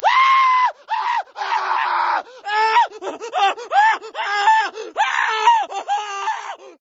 scream1.ogg